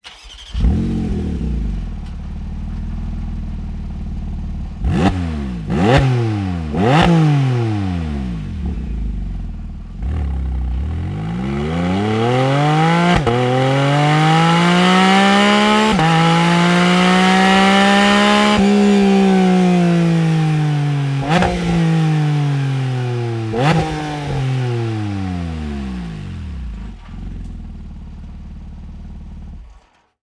SLIP-ON-RACE-LINE-Porsche-718-Cayman-GT4-RS.wav